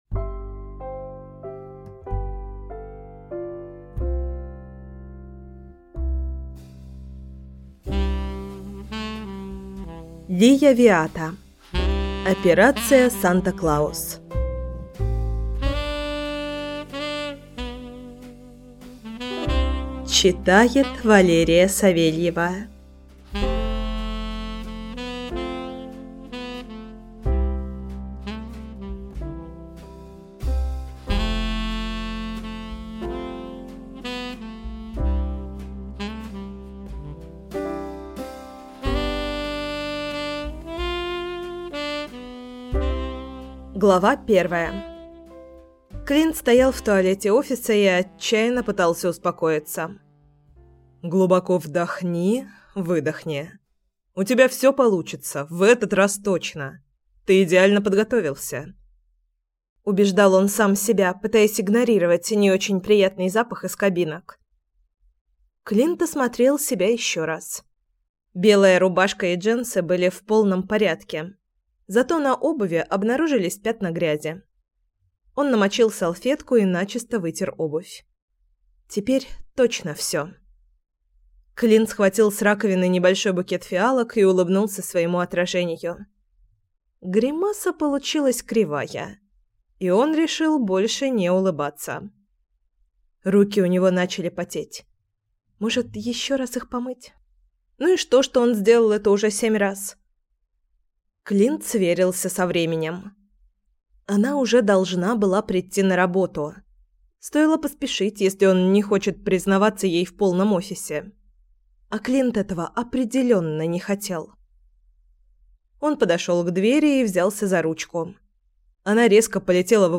Аудиокнига Операция: Санта-Клаус | Библиотека аудиокниг